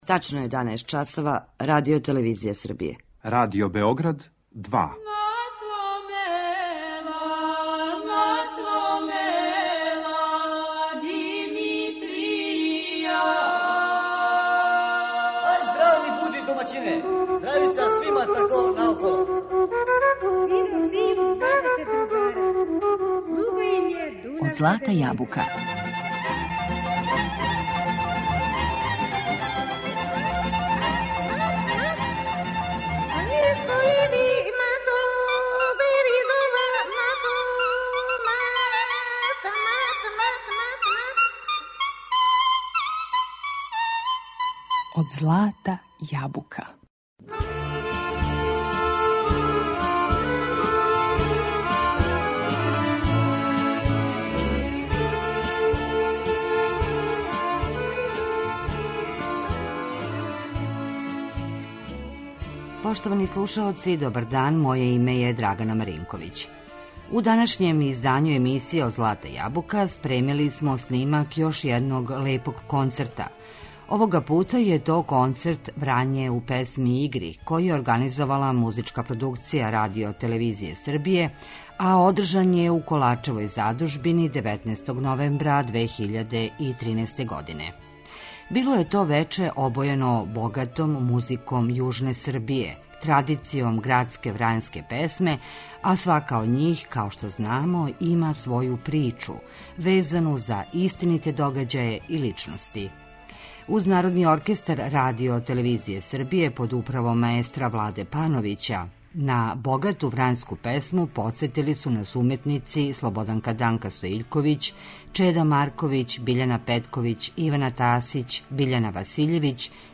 У данашњој емисији Од злата јабука, слушамо снимак концерта "Врање у песми и игри" који је организовала Музичка Продукција РТС-а у Коларчевој задужбини.
Био је то концерт обојен богатом музиком јужне Србије, традицијом градске врањске песме, а свака од њих , има своју причу везану за истините догађаје и личности.